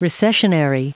Prononciation du mot recessionary en anglais (fichier audio)
recessionary.wav